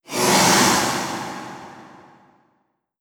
Jumpscare_14.wav